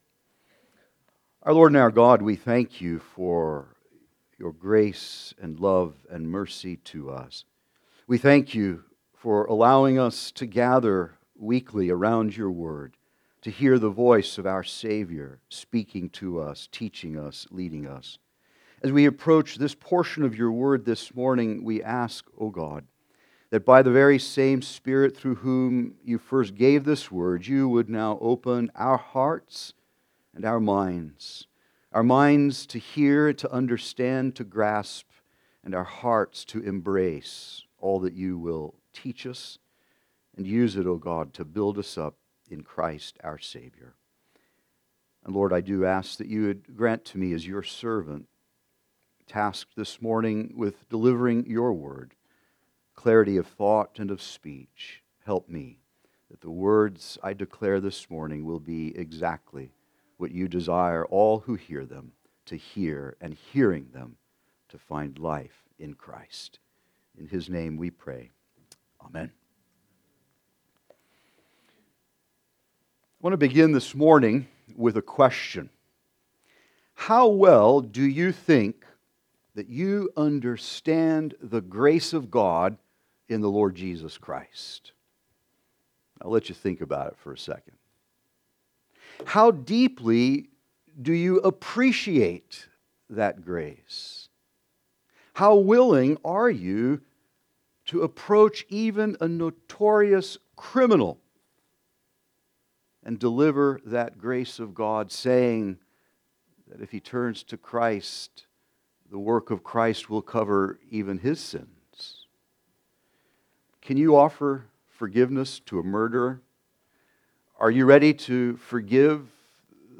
Guest Speaker Service Type: Sunday Service